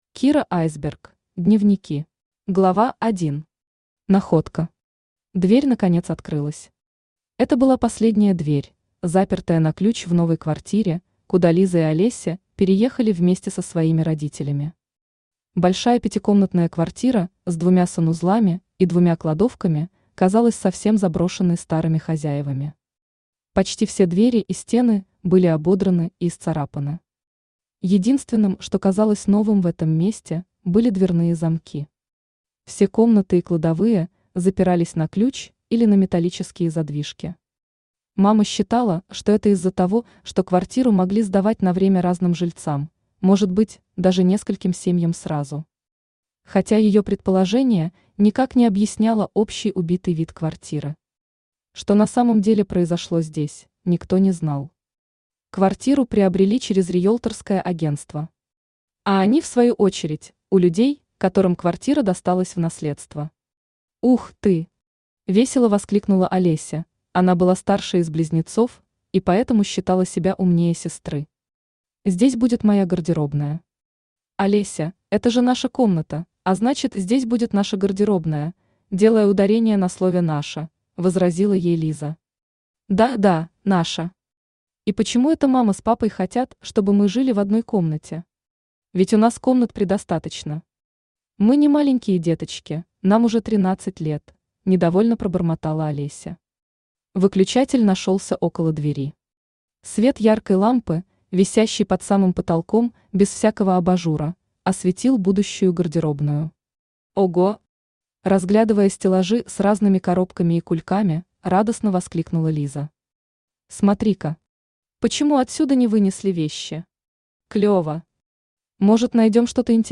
Aудиокнига Дневники Автор Кира Айсберг Читает аудиокнигу Авточтец ЛитРес.